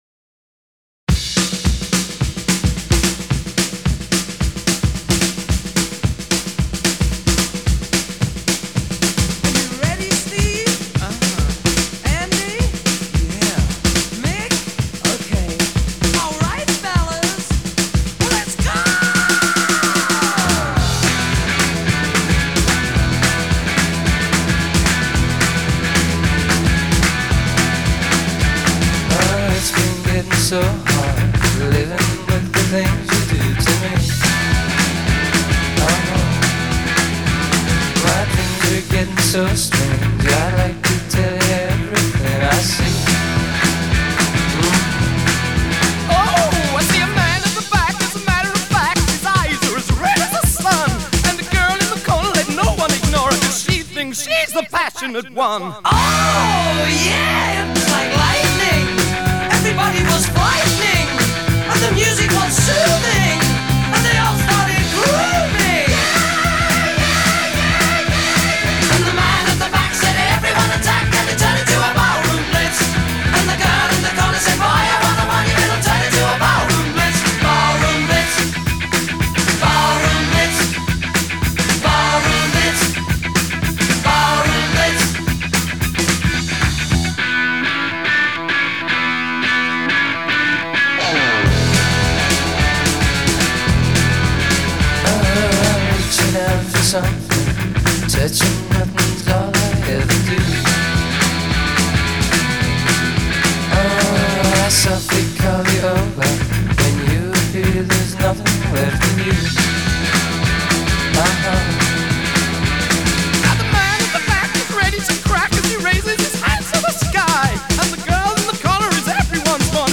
Genre: Glam Rock, Hard Rock